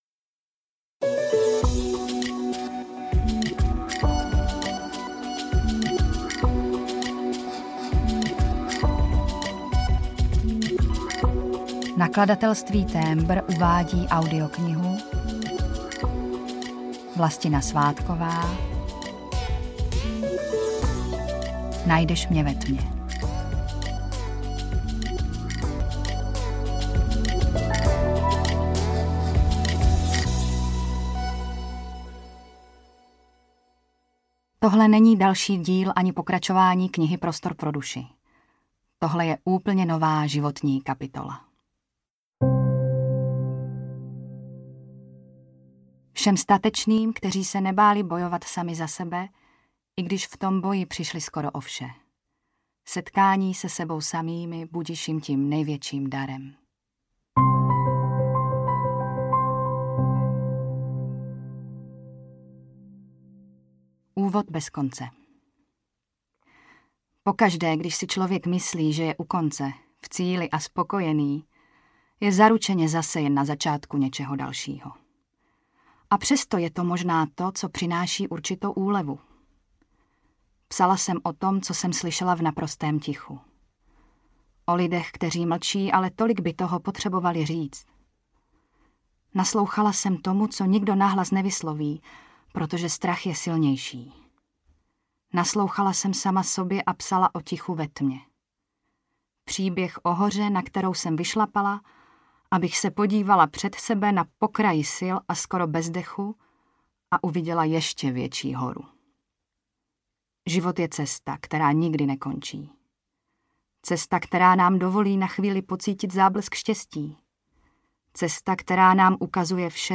Najdeš mě ve tmě audiokniha
Ukázka z knihy